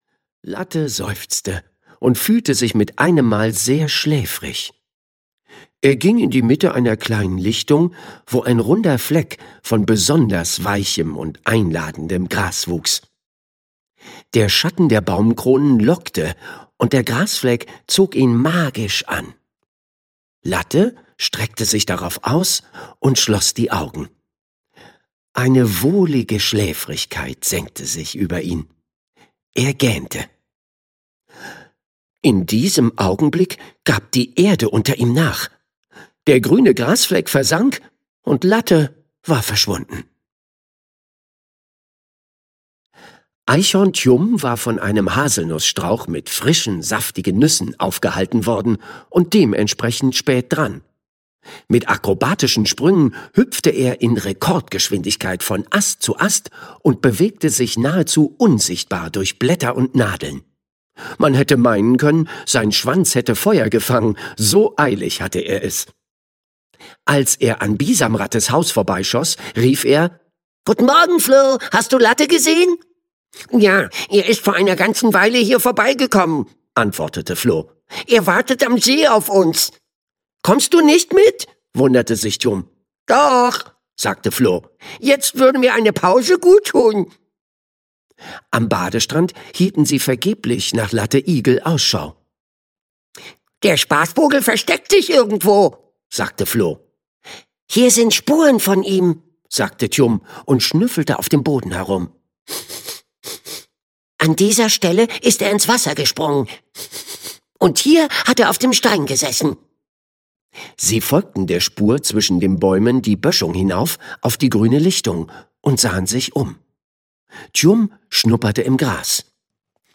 Latte Igel 3: Latte Igel und der Schwarze Schatten - Sebastian Lybeck - Hörbuch